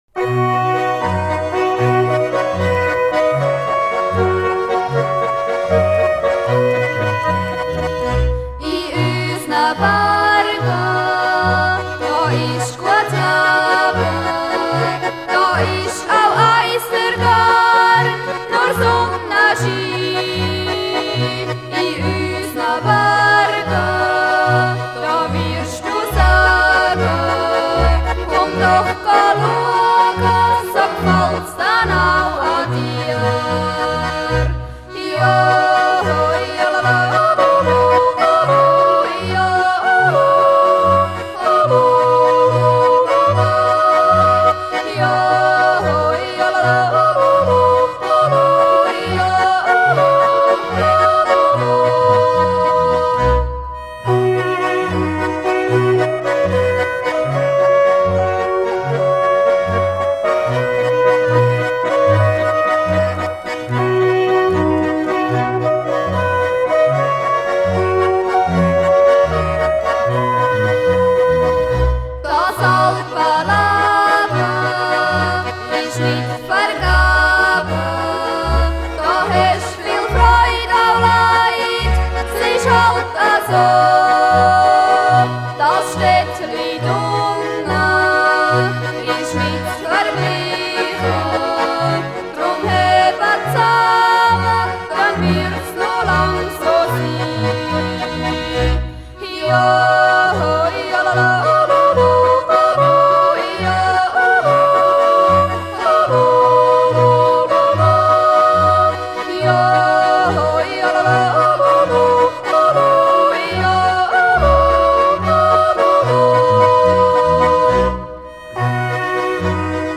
I üsna Bärge. Lied.